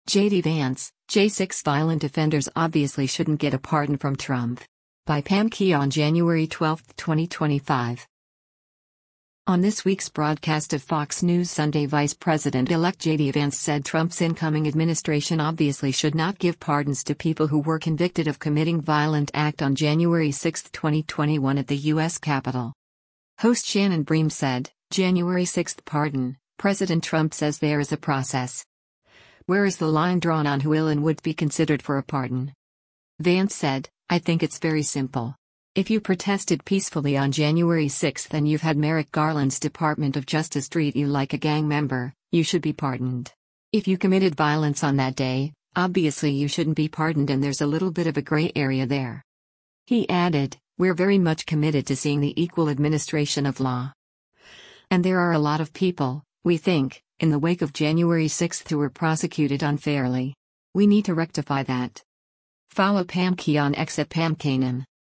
On this week’s broadcast of “Fox News Sunday” Vice President-elect JD Vance said Trump’s incoming administration “obviously” should not give pardons to people who were convicted of committing violent act on January 6, 2021 at the U.S. Capitol.
Host Shannon Bream said, “January 6 pardon, President Trump says there is a process. Where is the line drawn on who will and would’t be considered for a pardon?”